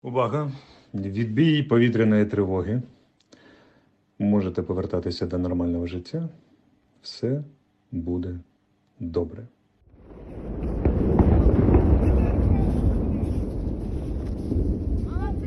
Oleksiy Arestovich announce the cancel of air raid siren
arestovich-air-raid-cancel.mp3